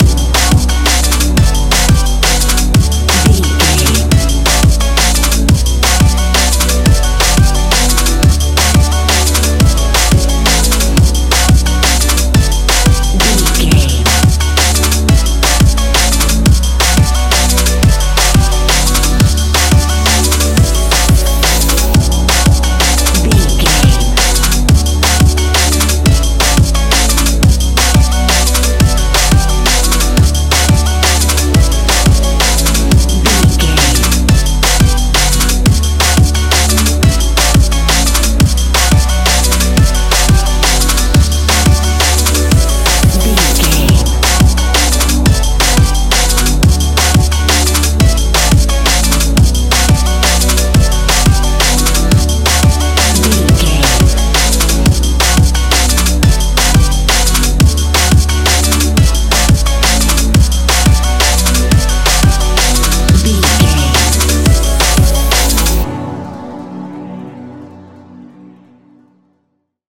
Ionian/Major
electronic
techno
trance
synthesizer
synthwave
instrumentals